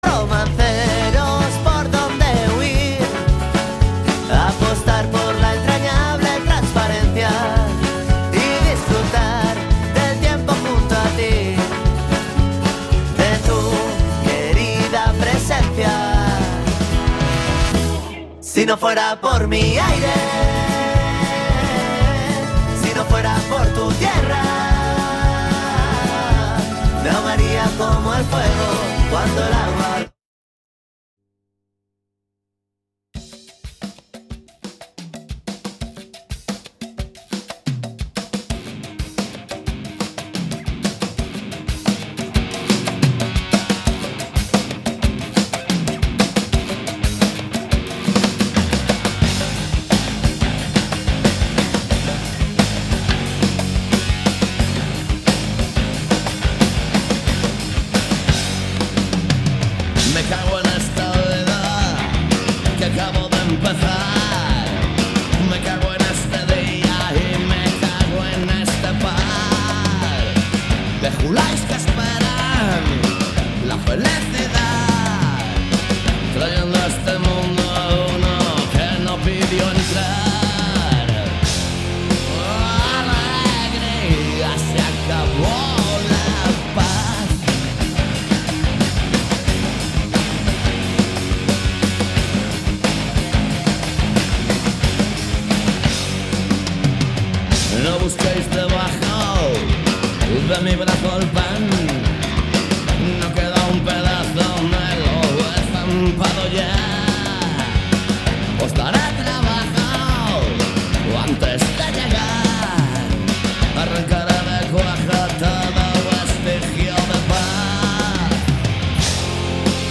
Todo iso misturado con boa música e un pouco de humor se o tema o permite. Cada martes ás 18 horas en directo.
Programa emitido cada mércores de 19:00 a 20:00 horas.